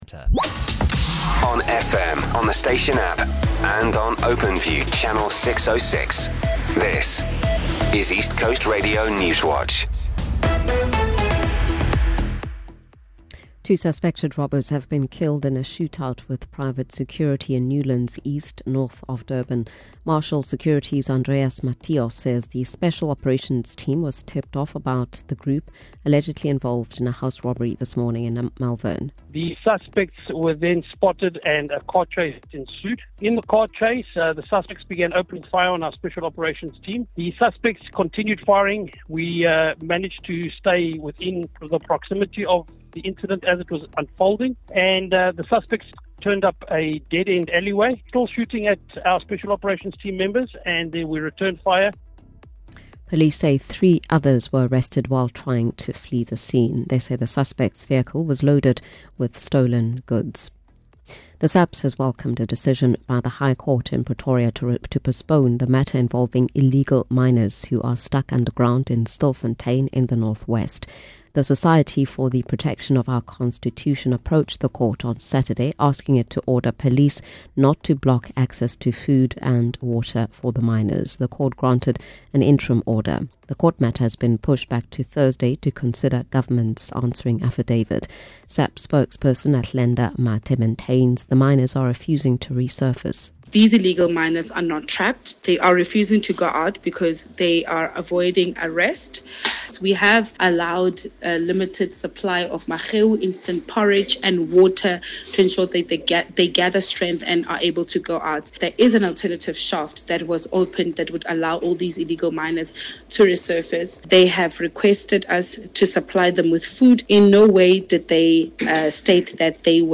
Here’s your latest ECR Newswatch bulletin from the team at East Coast Radio.